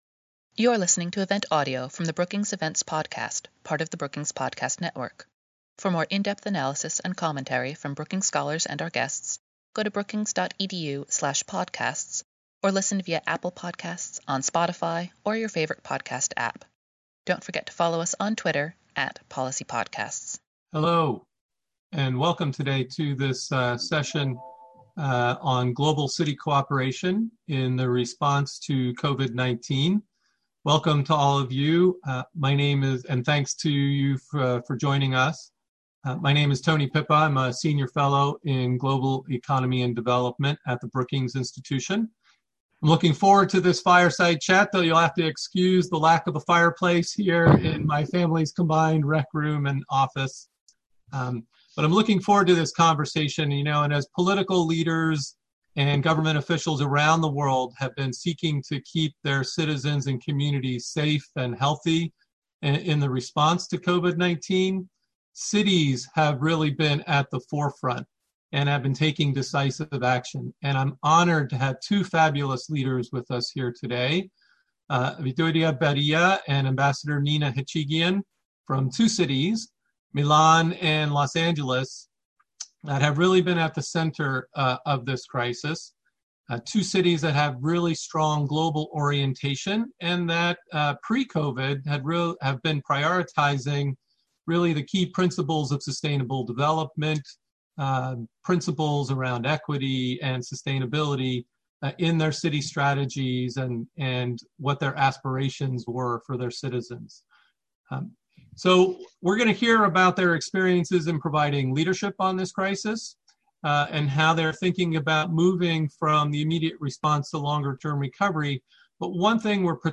Webinar: Global city cooperation in the response to COVID-19 | Brookings
On Thursday, April 23, the Global Economy and Development program at Brookings hosted a conversation to discuss the relevance and challenges of of city-to-city cooperation in the response to COVID-19.
The panel then answered questions that had been submitted online.